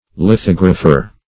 lithographer \li*thog"ra*pher\, n.